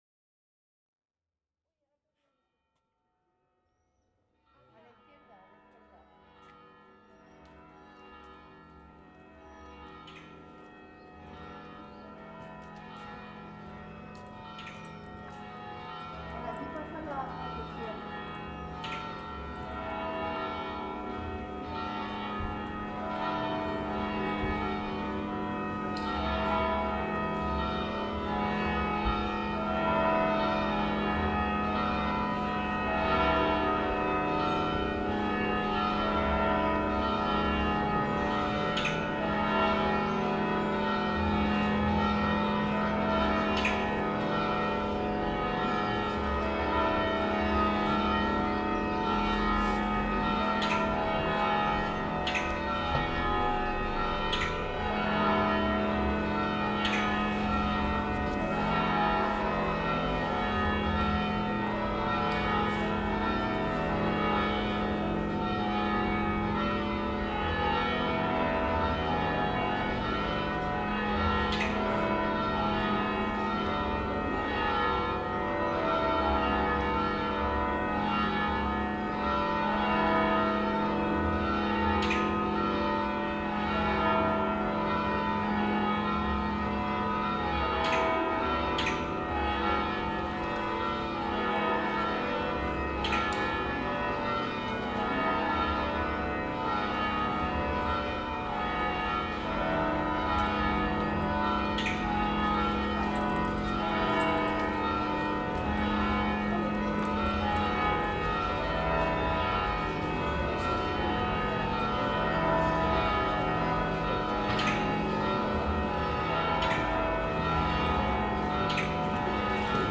31.2 CAMPANES TOC DE CRIDA Grup de Campaners de la Catedral
Santa Tecla Tarragona